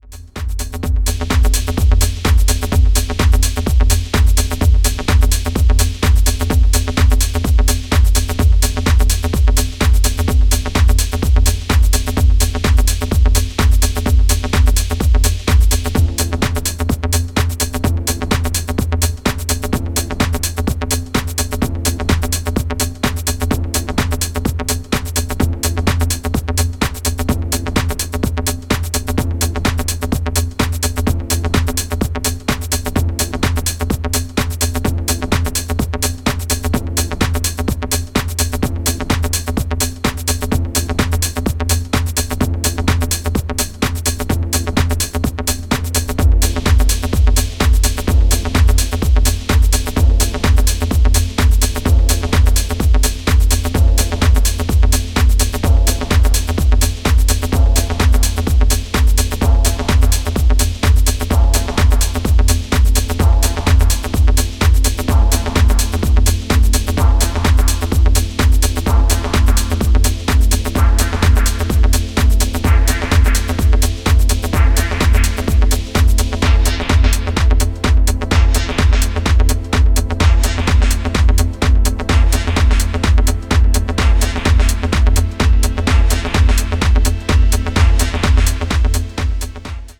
minimal, funk techno sounds that defined his earlier career
It’s contemporary techno